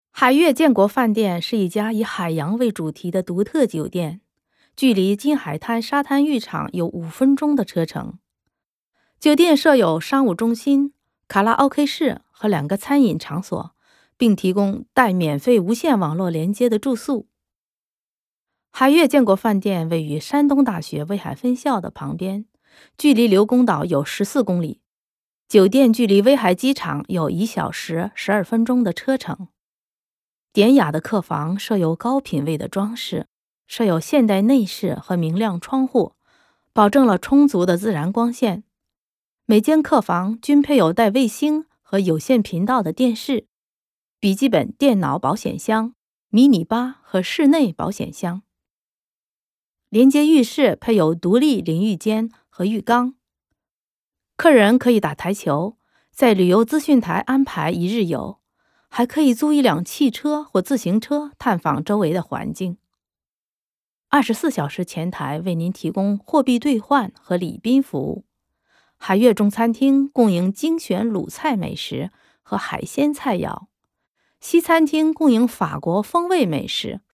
Voix off
Reportage radio